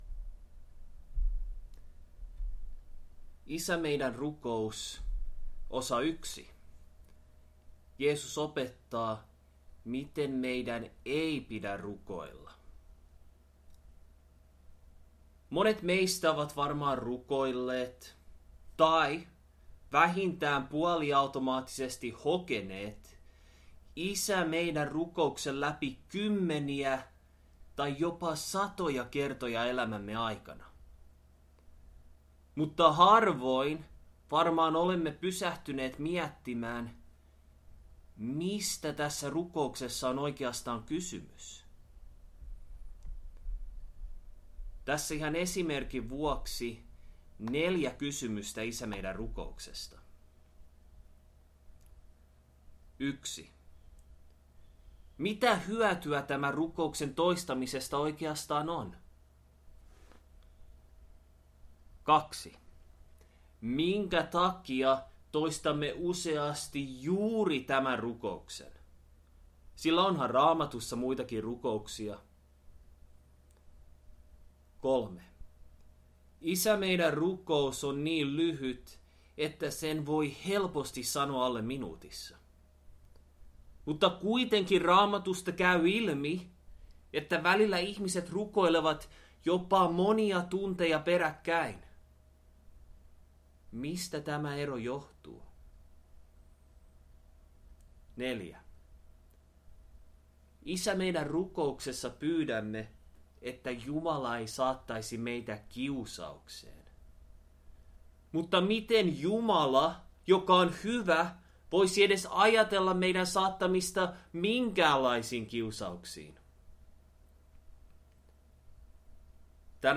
Tänään alkavassa saarnasarjassa käymme läpi koko Isä meidän -rukouksen ja vastaamme kaikkiin näihin sekä moniin muihin mielenkiintoisiin kysymyksiin.